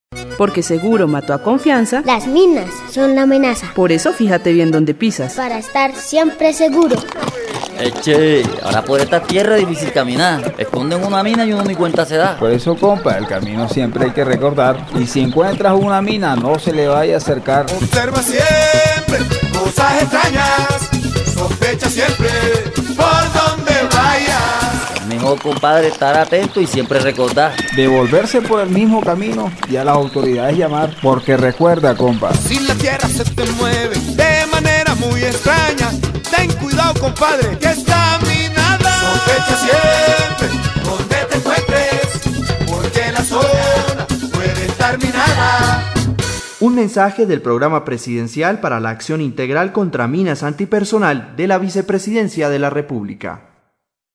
La campaña incluye siete comerciales de televisión sobre los riesgos y las orientaciones para asumir comportamientos seguros; cinco cuñas radiales con los mensajes básicos de prevención y las voces de importantes artistas como Maia y el grupo San Alejo, entre otros.
Cuñas radiales